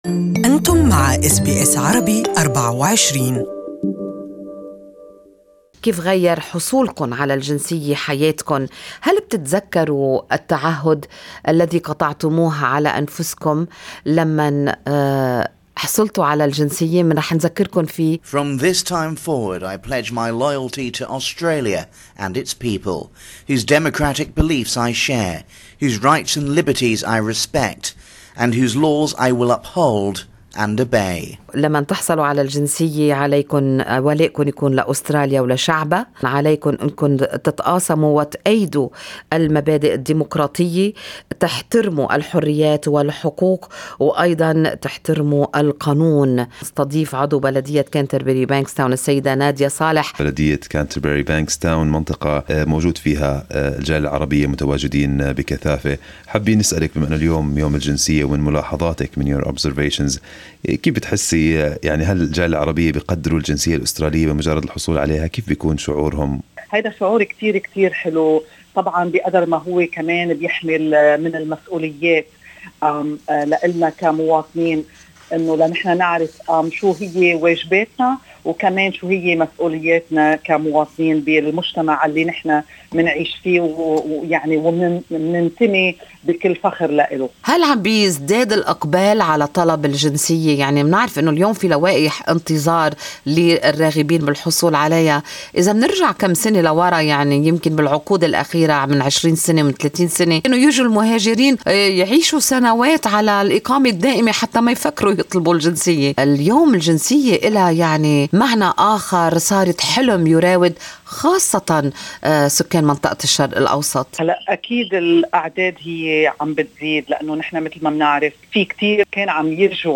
Good Morning Australia interviewed Canterbury-Bankstown councillor Nadia Saleh to talk about ceremonies held by the council for citizenship. Mrs. Saleh also highlighted the importance of educating immigrants on their main duties as Australian citizens.